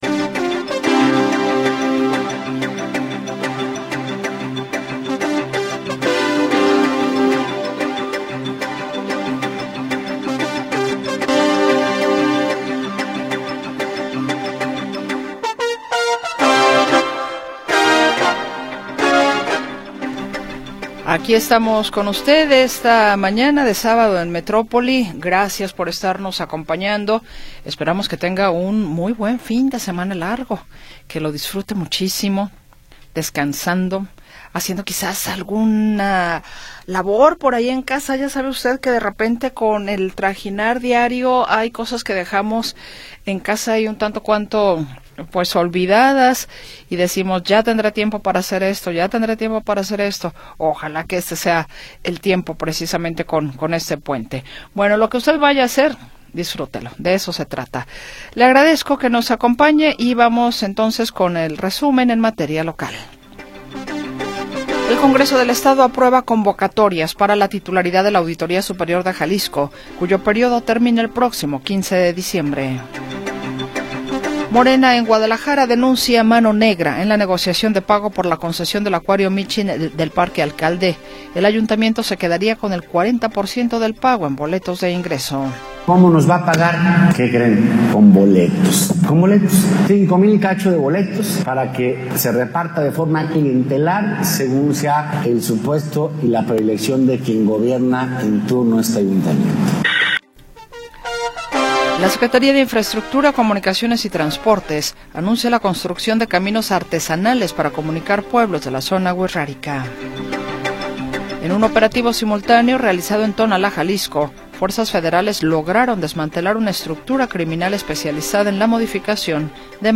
Noticias y entrevistas sobre sucesos del momento